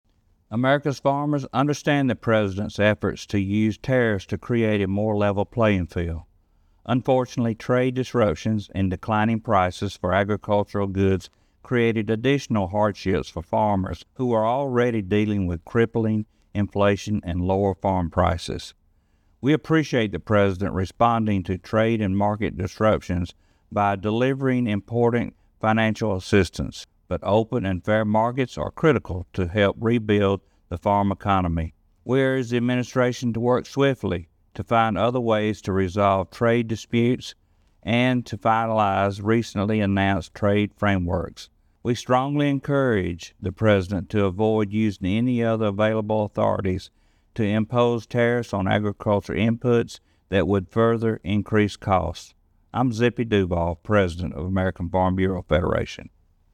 In this week’s commentary, American Farm Bureau President Zippy Duvall tells us approximately 20-percent of economic activity starts at the farmgate.